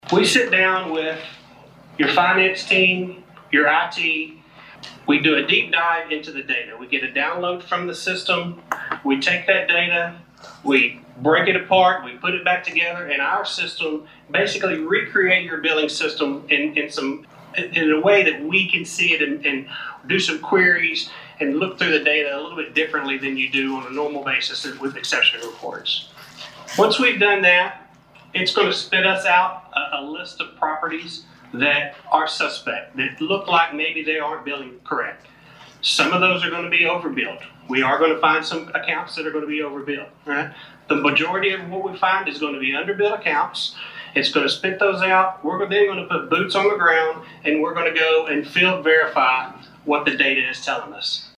At this week’s Huntsville City Council Workshop